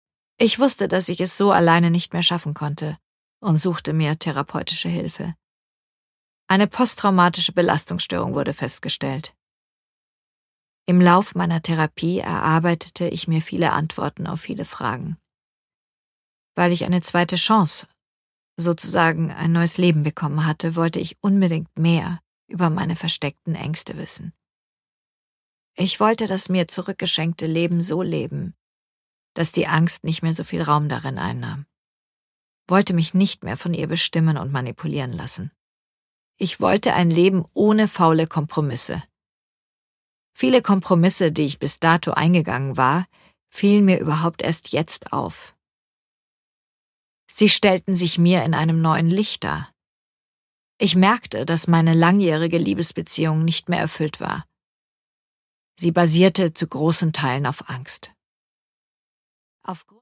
Produkttyp: Hörbuch-Download
Gelesen von: Ursula Karven